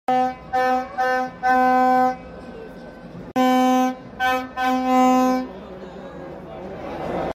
Ever wondered what makes stadiums sound like a buzzing beehive? It's the legendary vuvuzela! This iconic horn became famous during the 2010 FIFA World Cup, filling the air with its loud, droning sound.